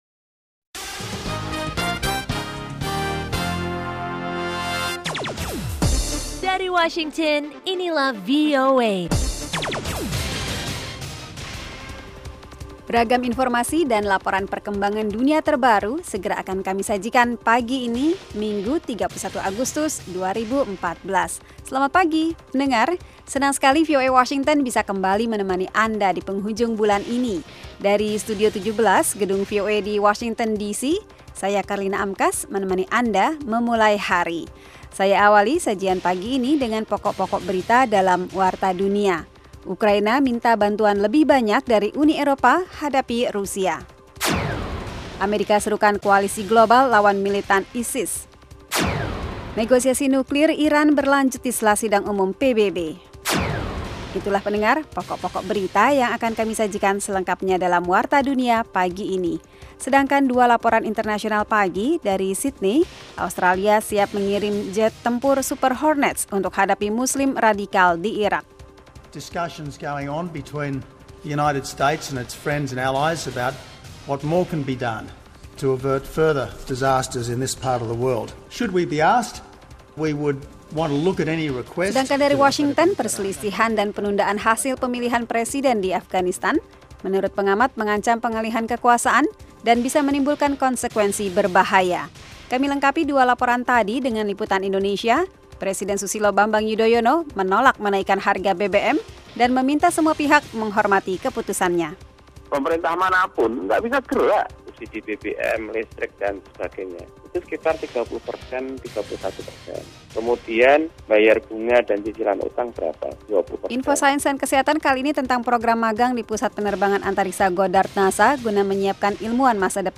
Setiap paginya, VOA menyiarkan sebuah program informatif yang menghidangkan beragam topik yang menarik, berita internasional dan nasional, tajuk rencana, bisnis dan keuangan, olah raga, sains dan kesehatan, musik dan tips-tips pengembangan pribadi.